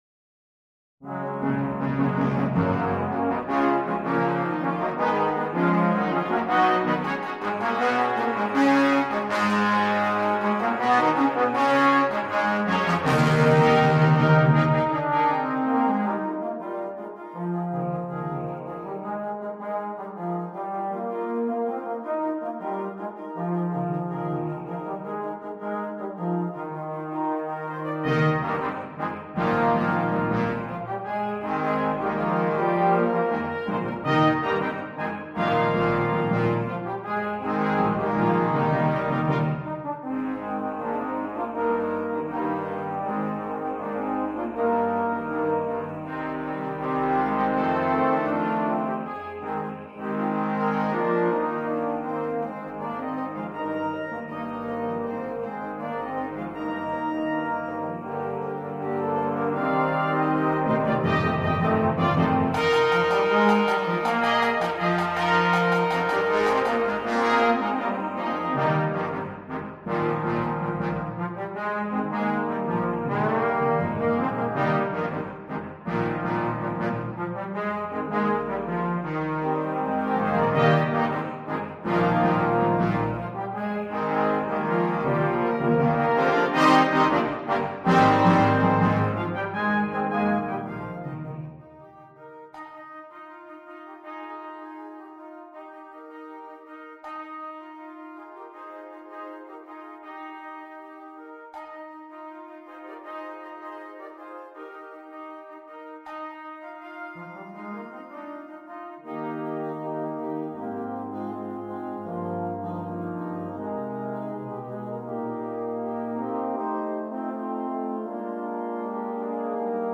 Noten für Brass Band
ohne Soloinstrument
Originalkomposition, Unterhaltung